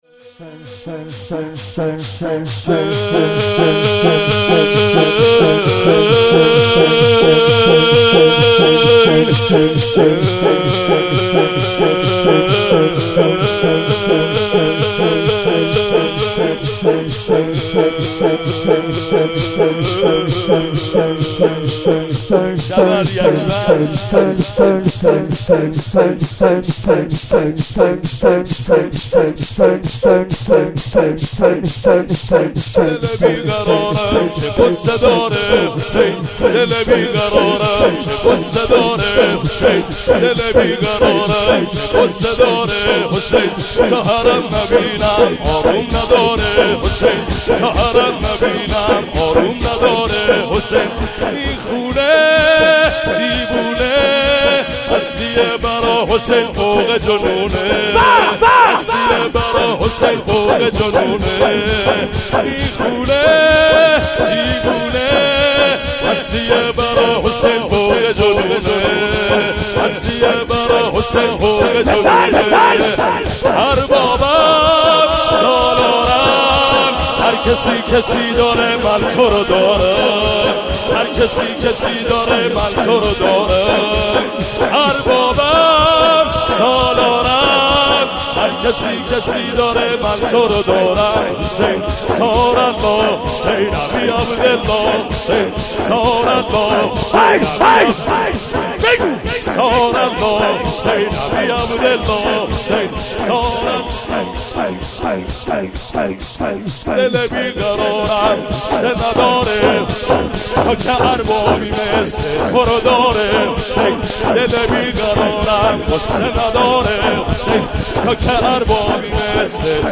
05.sineh zani.mp3